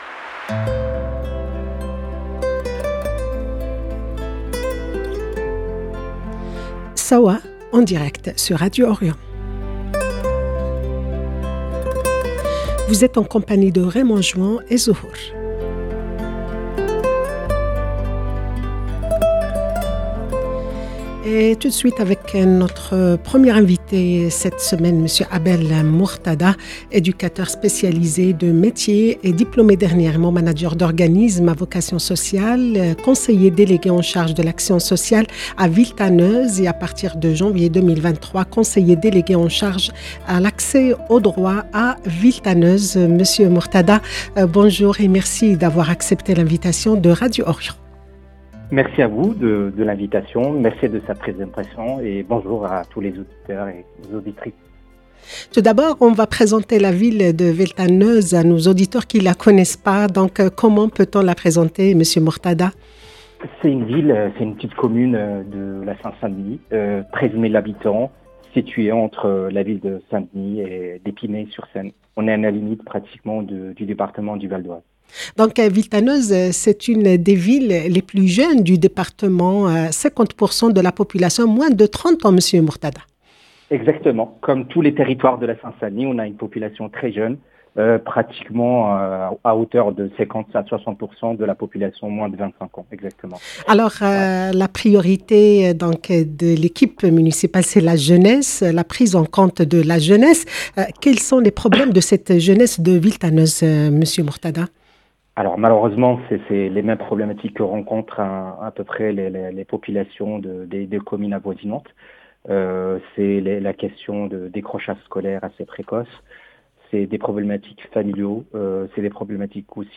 Sawa 20 décembre 2022 - 8 min 59 sec Sawa avec Abel Mortada conseiller municipal à Villetaneuse LB Sawa L'invité de SAWA sur Radio Orient : Monsieur Abel MORTADA , éducateur spécialisé, Manager d'organismes à vocation sociale, conseiller délégué chargé de l'action sociale à Villetaneuse, pour parler de l'actualité sociale, situation socio-économique des familles et des jeunes de la Seine-Saint-Denis à la suite de la crise du COVID19. 0:00 8 min 59 sec